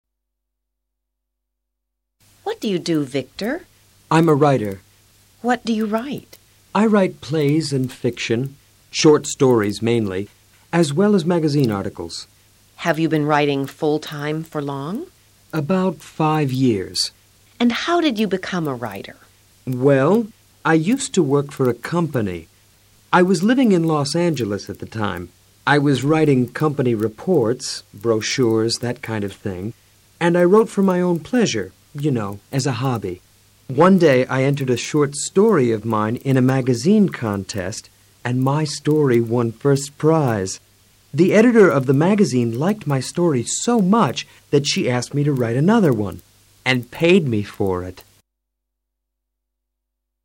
Tres personas exponen cómo lograron alcanzar sus objetivos profesionales.